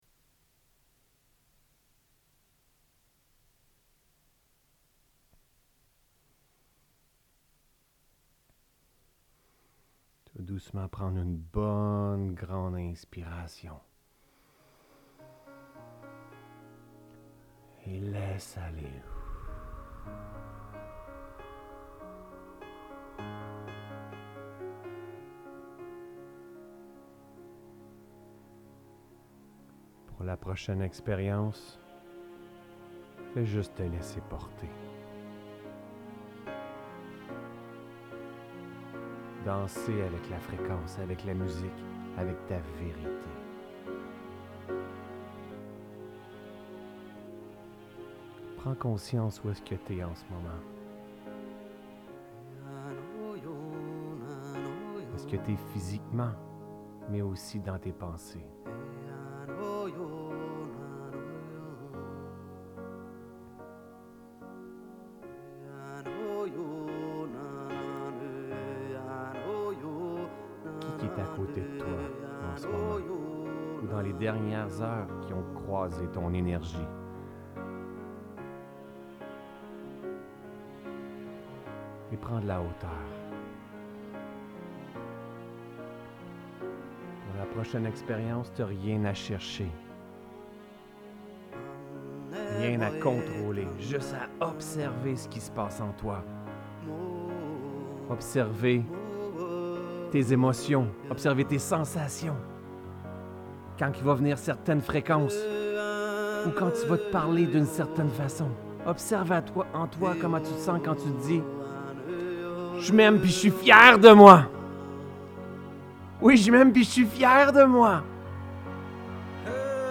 Méditation guidée - L'amour de soi